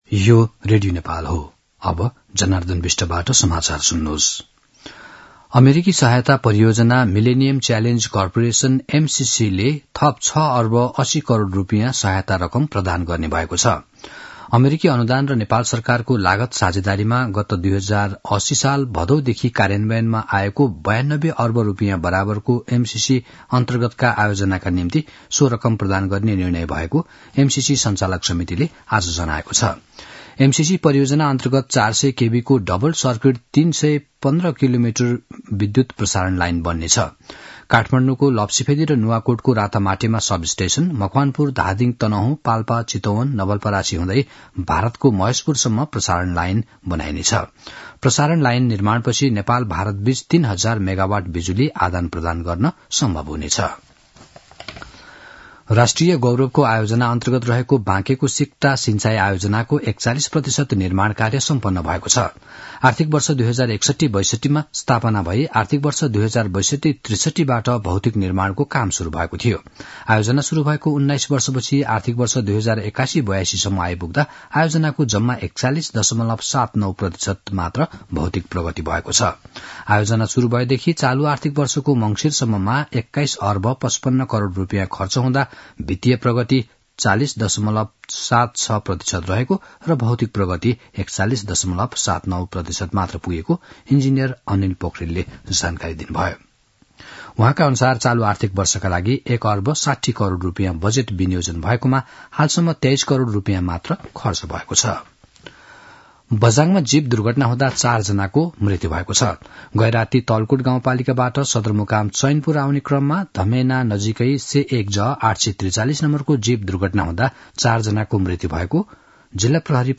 मध्यान्ह १२ बजेको नेपाली समाचार : २० पुष , २०८१
12-am-nepali-news-1-2.mp3